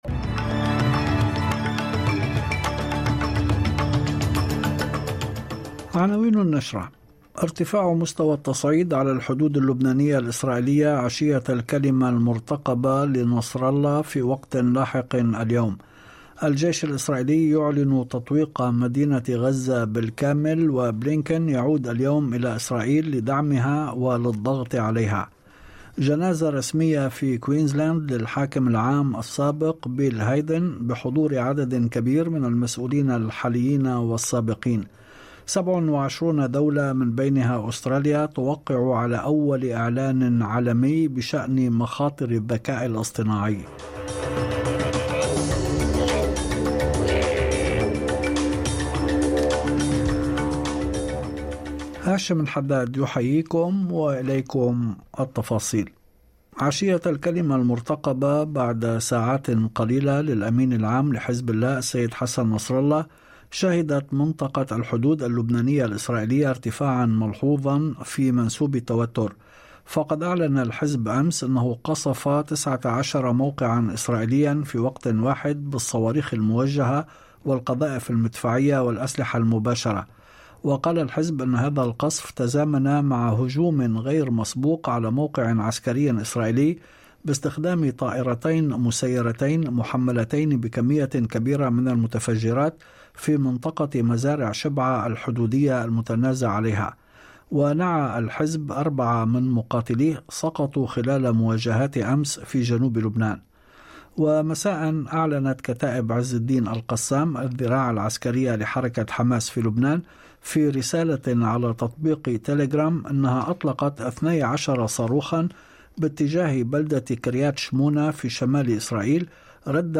نشرة أخبار المساء 03/11/2023